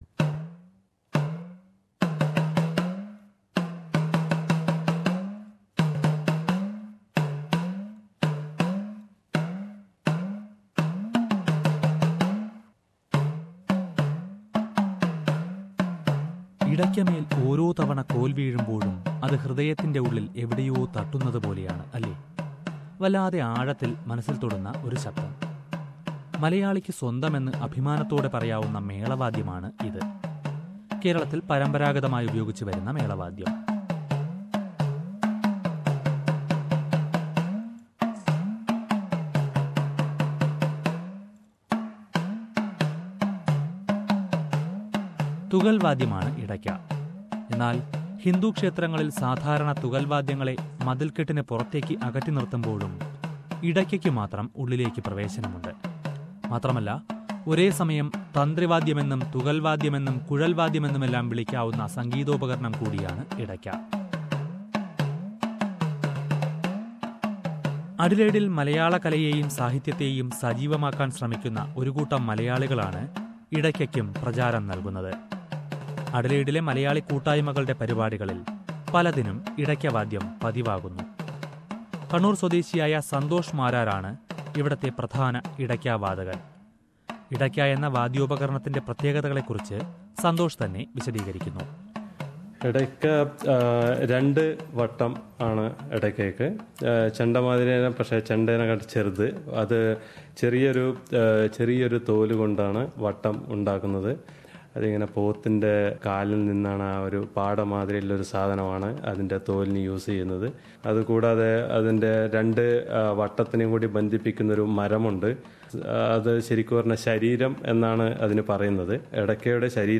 Edakka is a unique percussion instrument which is played mostly in Kerala. In a time when many olden art forms are losing their value, efforts by a few malayalees in Adelaide is making edakka appealing to many. Let us listen to a report on this.